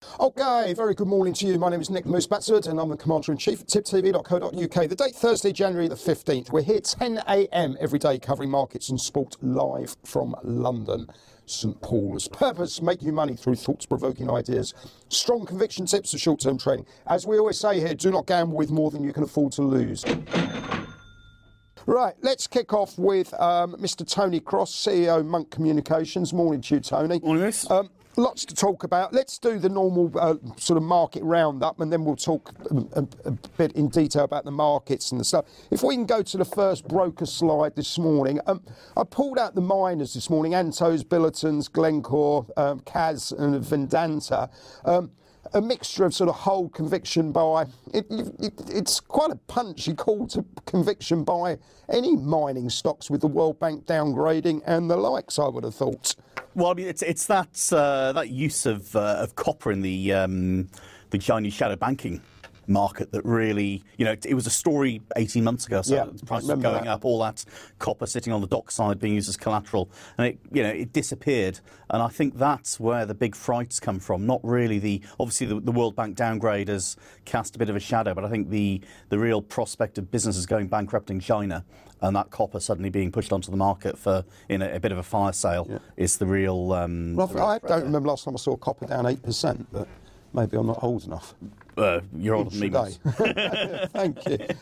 Live Market Round-Up & Soapbox thoughts.